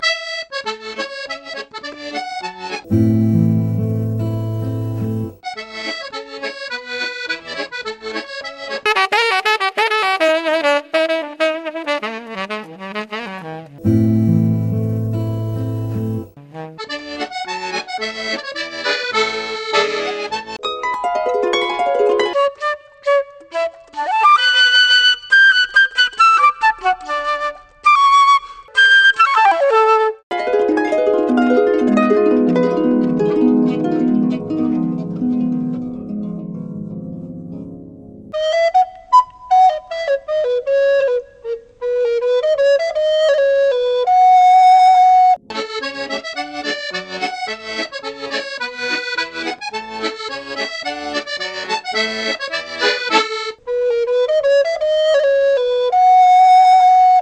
Chaque instrument joue toujours le même morceau.